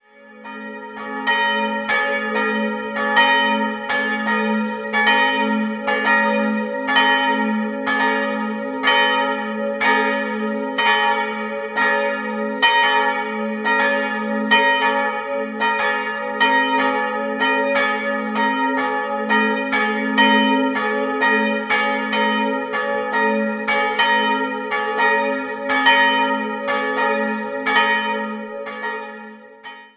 2-stimmiges Geläute: as'-b'
Glocke 1
as'+5
Glocke 2
b'+1